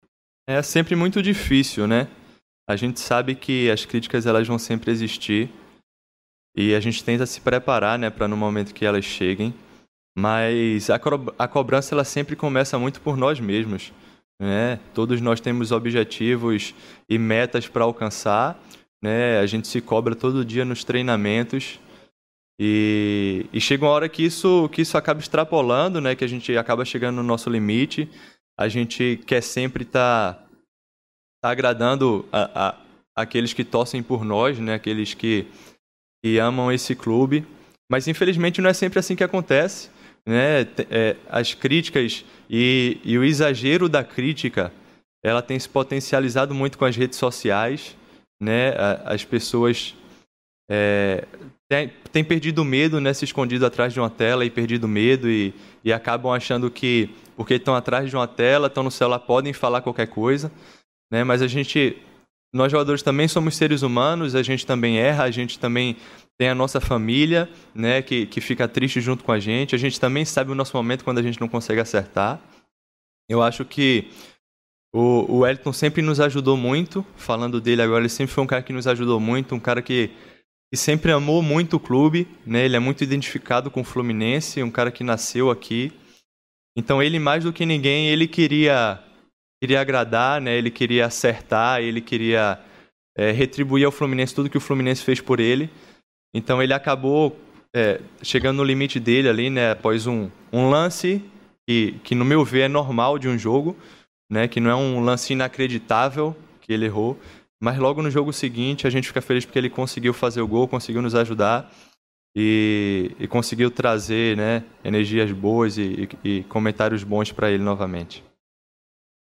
O zagueiro Nino que voltou a ser titular da zaga do Fluminense na partida contra o Botafogo foi o escolhido para a entrevista desta quarta-feira (27.01) e entre vários assuntos que respondeu das perguntas dos jornalistas, ele contestou as críticas que estão sendo feitas ao time tricolor que não tem jogado bem nas últimas partidas.
Acompanhe aqui outros temas da coletiva: